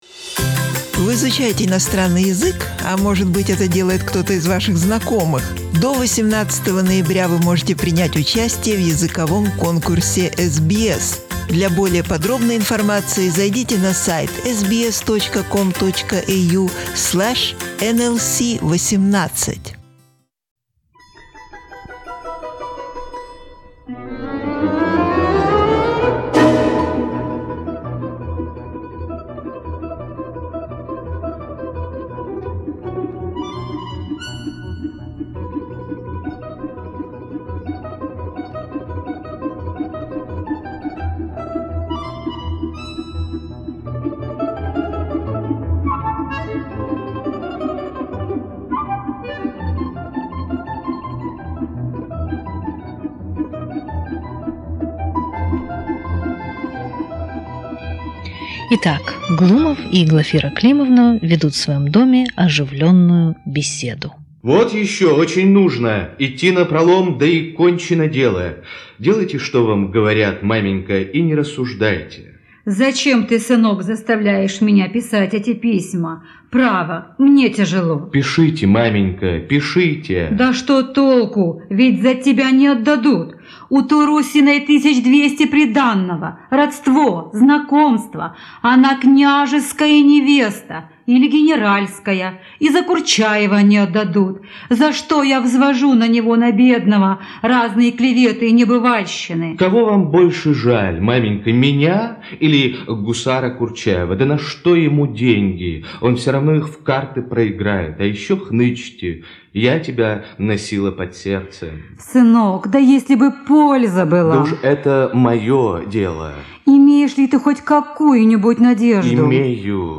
His energy attracted to the studio many amateur and professional actors of different ages from many different cities of the former USSR.
Some fragments of this play were later shown on the stage for a the audience.